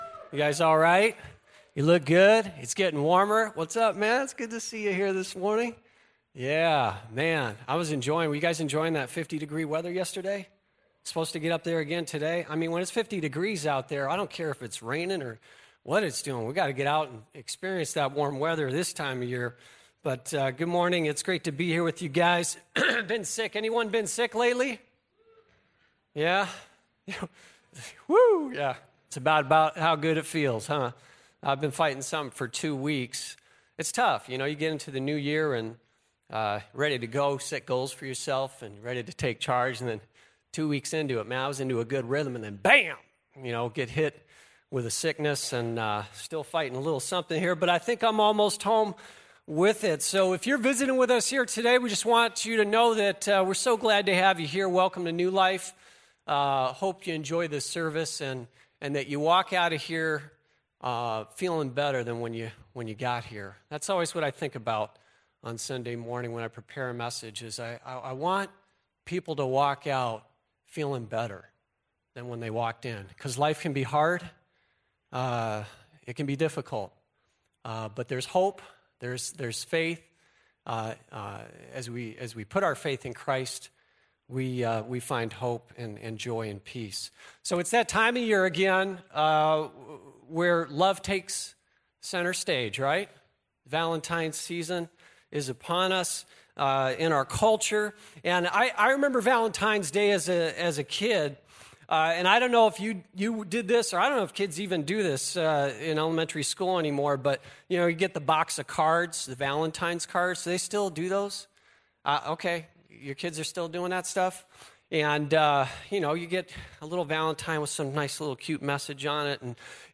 Audio Sermon Save Audio Save PDF https